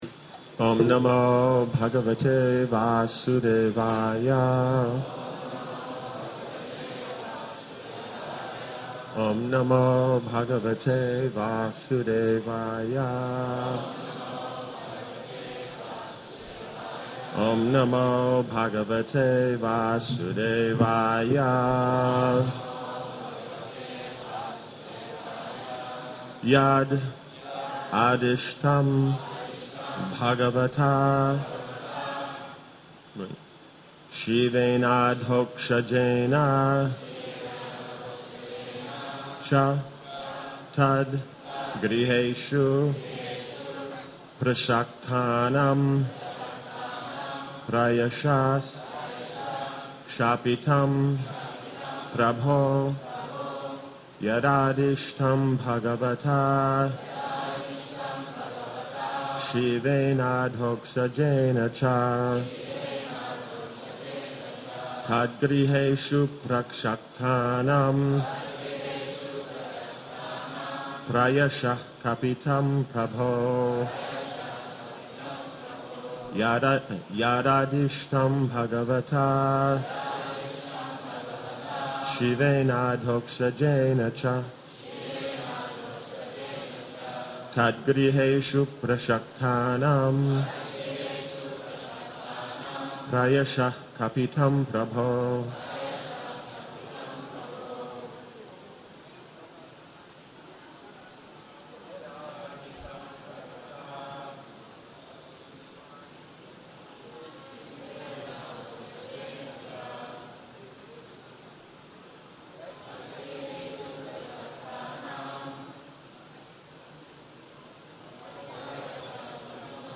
A collection of audio lectures on various topics, previously available from the ISKCON Desire Tree website.